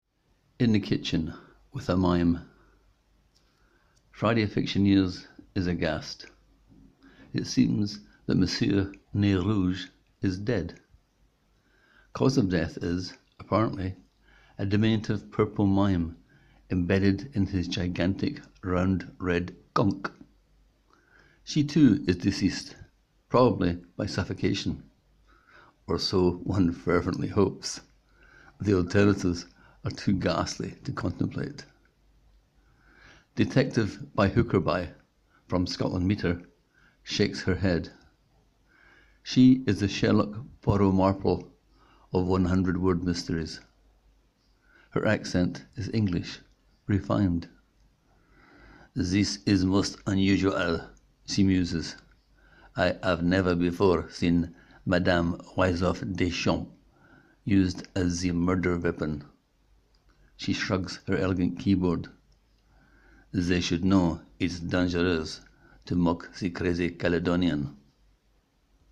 Click here to hear the writer read his words:
I am a Scot who lives in France, hence the silly accents.